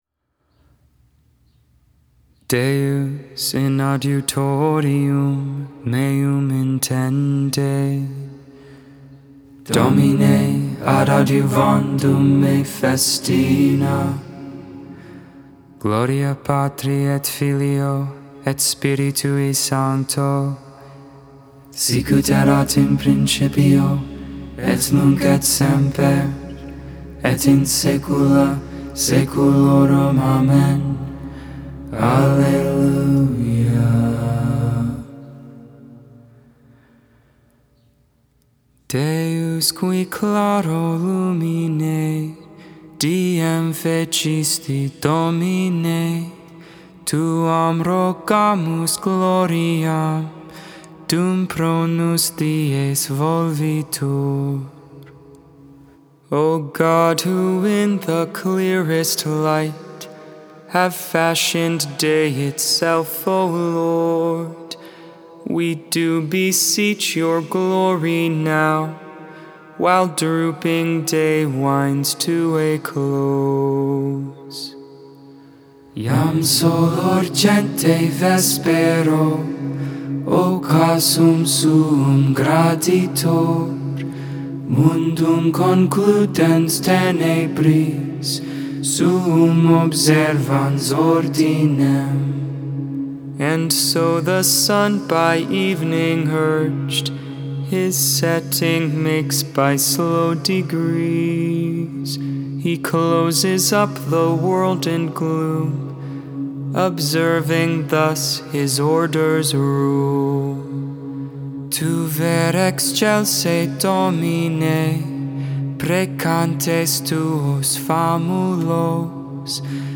7.7.22 Vespers, Thursday Evening Prayer